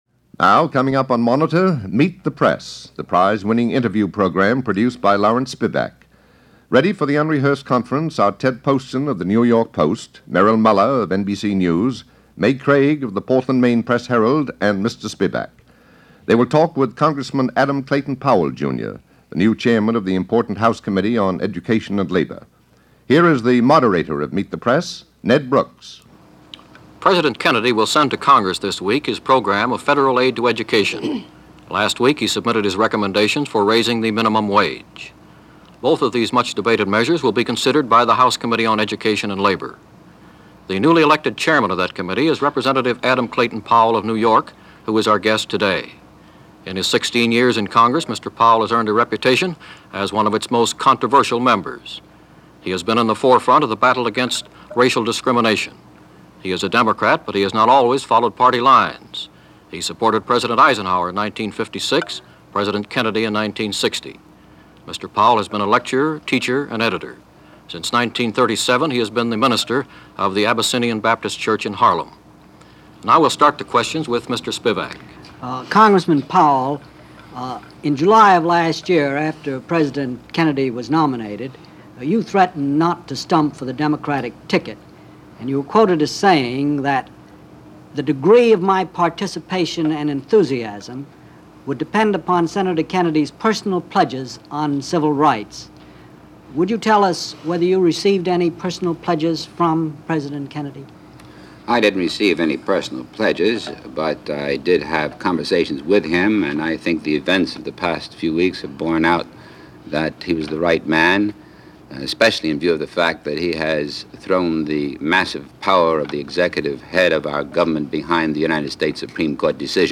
The subject, naturally, is the Chairmanship and what Powell’s plans are for this committee in the coming year, as well as discussing his colorful and often controversial career, including questions regarding recent income Tax inquiries. A lively and informative interview and a glimpse of one of the important, though not all that well remembered today, figures in African-American life, politics and civil rights.